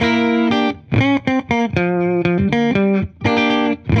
Index of /musicradar/dusty-funk-samples/Guitar/120bpm
DF_70sStrat_120-G.wav